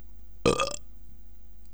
rot.wav